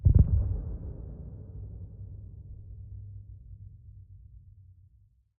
Minecraft Version Minecraft Version snapshot Latest Release | Latest Snapshot snapshot / assets / minecraft / sounds / mob / warden / nearby_close_2.ogg Compare With Compare With Latest Release | Latest Snapshot